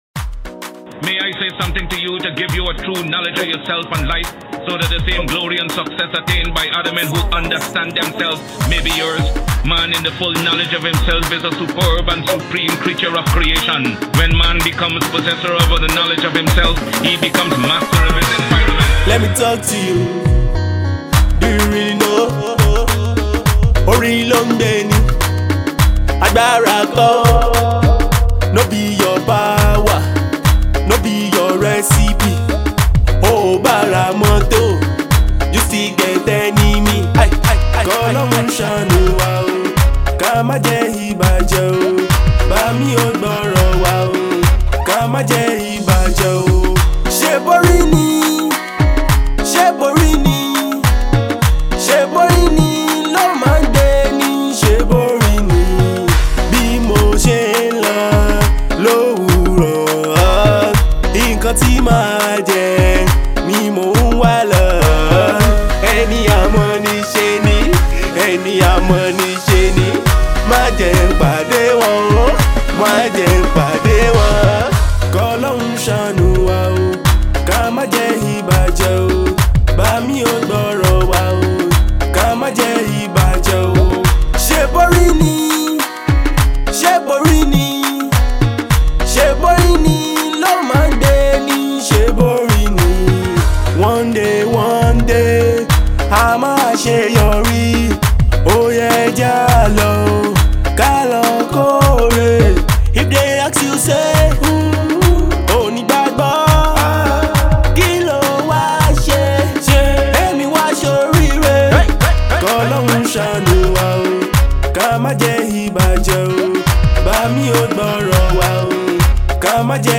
Afro-Pop Singer